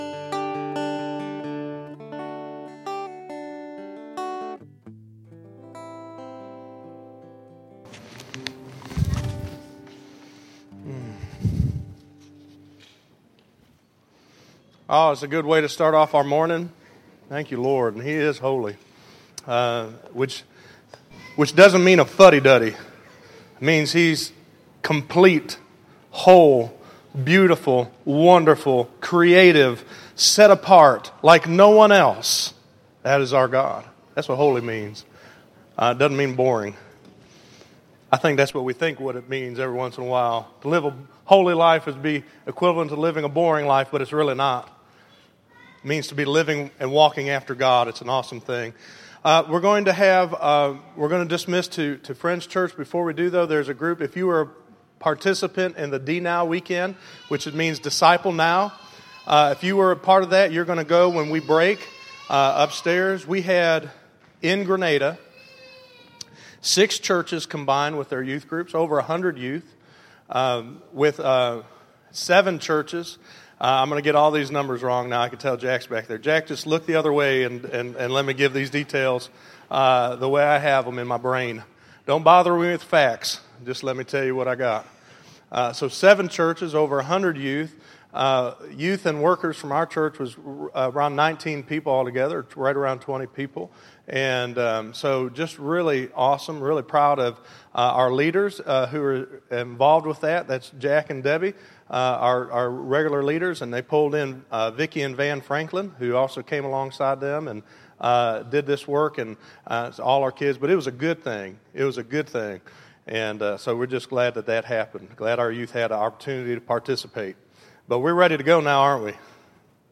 Listen to When Jesus Wins Wins We All Win - 02_02_2014_Sermon.mp3